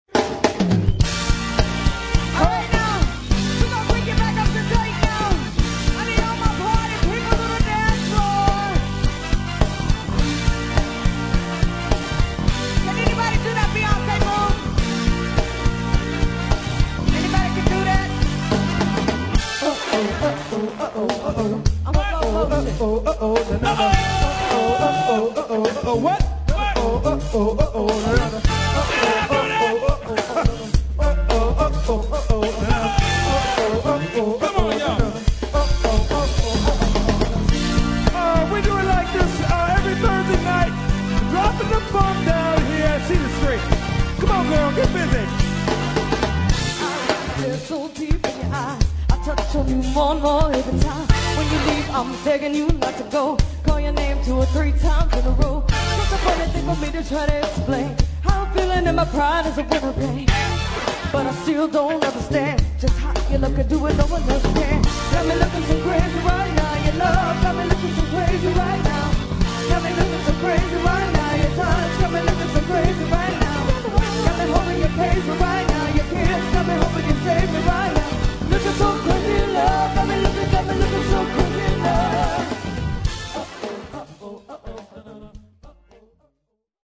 funk and R&B